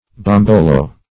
Bombolo \Bom"bo*lo\, n.; pl.